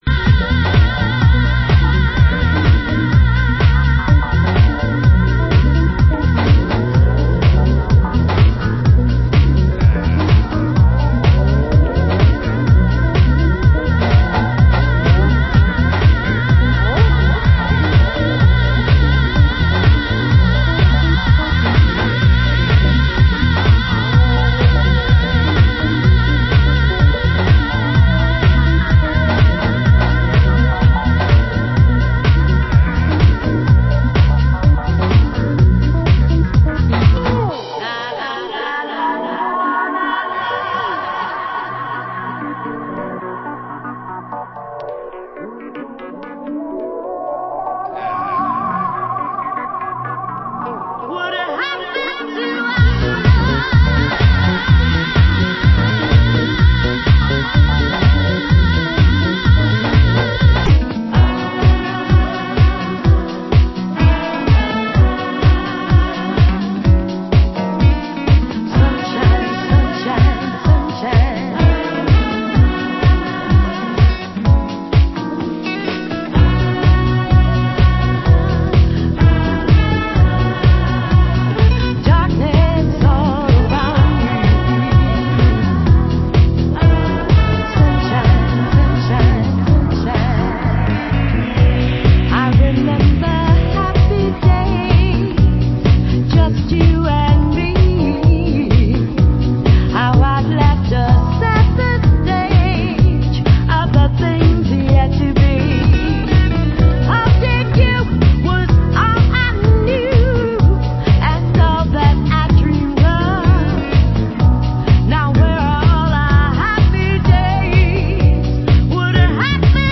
Genre Deep House